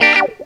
GTR 86 GM.wav